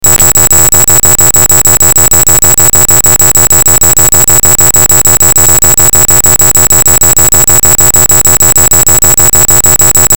Consumer B Gone enables you to block a shopping cart by just playing some tones out of your mobile phone speaker as a song or as a ringtone.